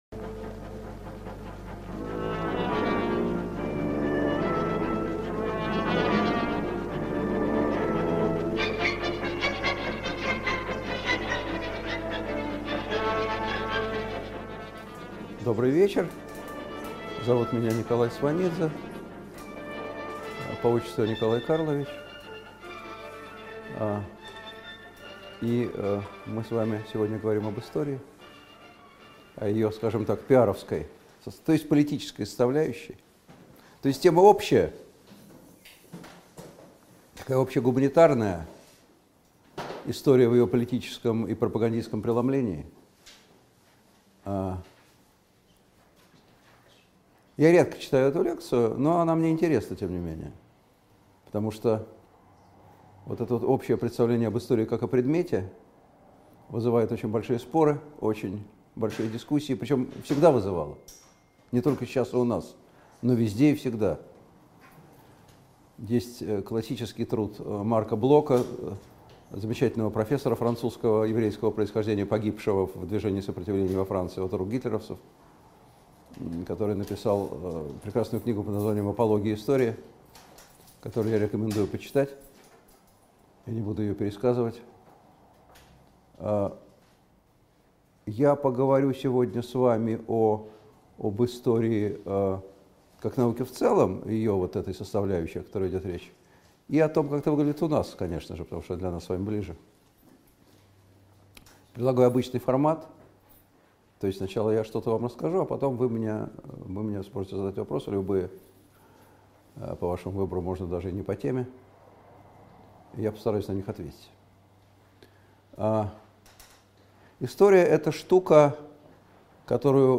Аудиокнига История как инструмент политического PR | Библиотека аудиокниг
Aудиокнига История как инструмент политического PR Автор Николай Сванидзе Читает аудиокнигу Николай Сванидзе.